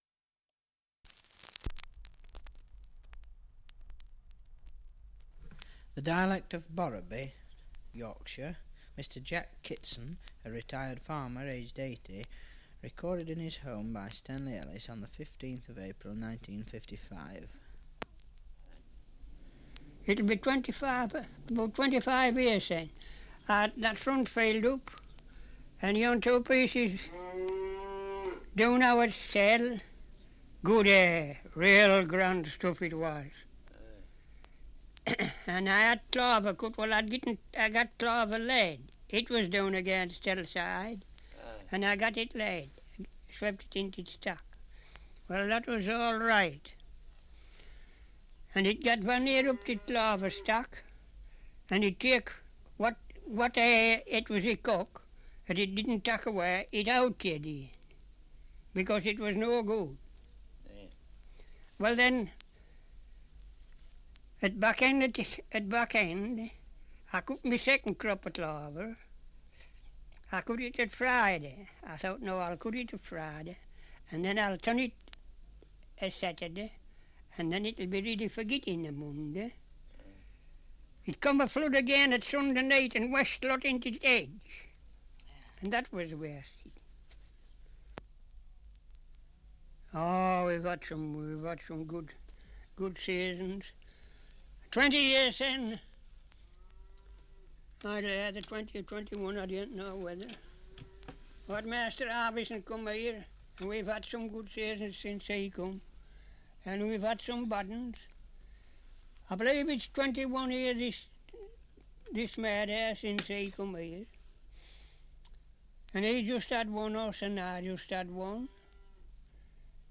Survey of English Dialects recording in Borrowby, Yorkshire
78 r.p.m., cellulose nitrate on aluminium